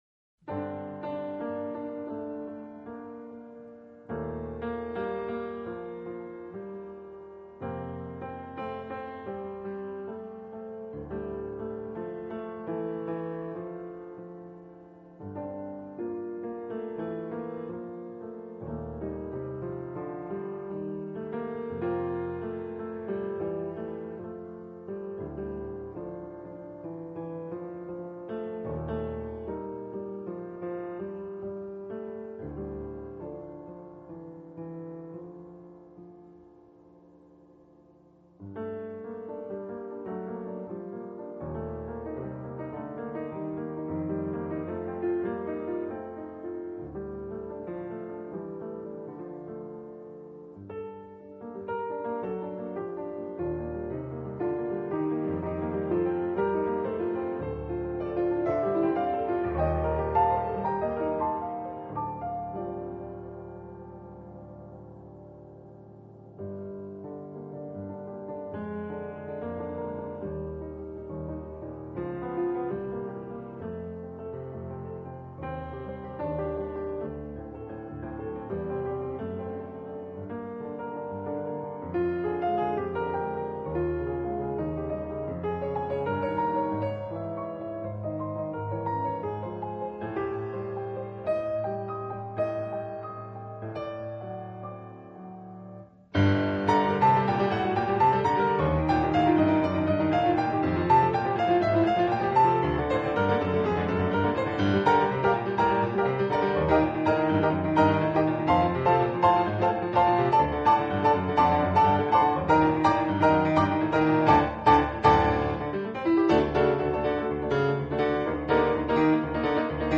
【爵士钢琴】
GENRE: Jazz